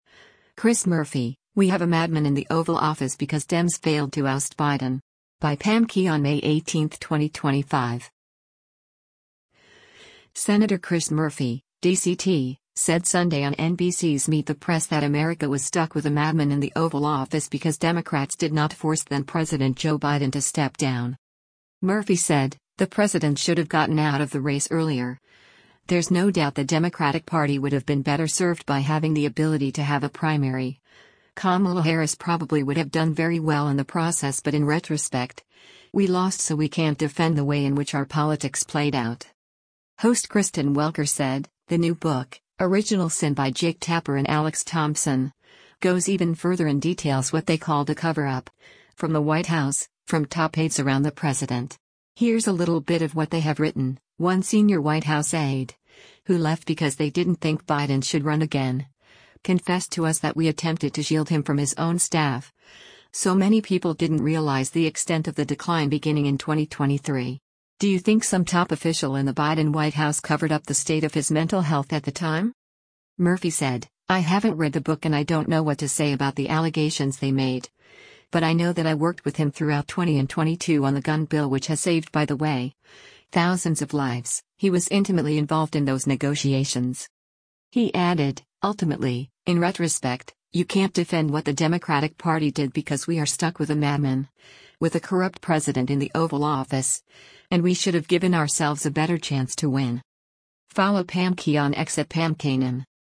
Senator Chris Murphy (D-CT) said Sunday on NBC’s “Meet the Press” that America was stuck with a “madman” in the Oval Office because Democrats did not force then-President Joe Biden to step down.